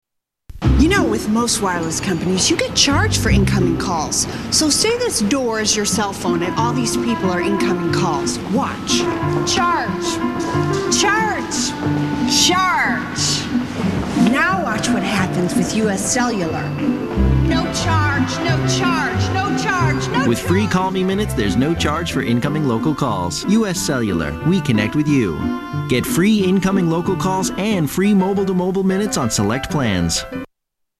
Tags: Television Awful Commericals Commercials Bad Commercial Media